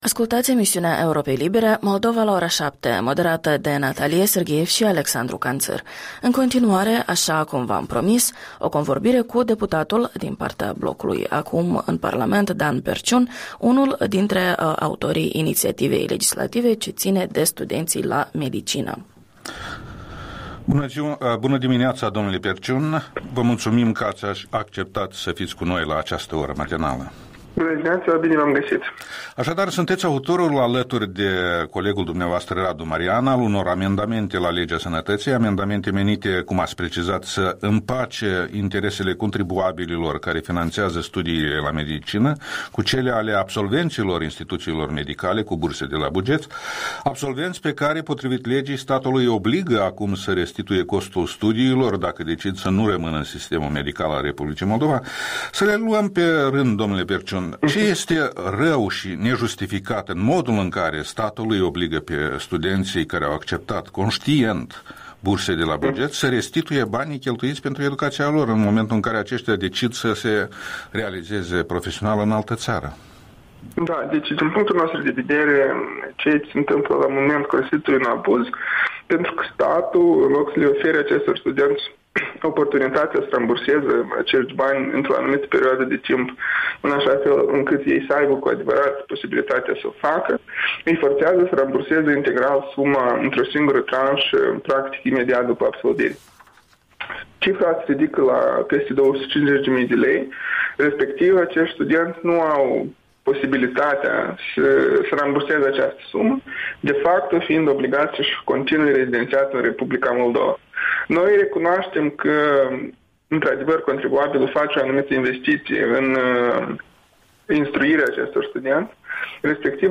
Interviul dimineții cu un deputat PAS despre situația tinerilor medici din Moldova.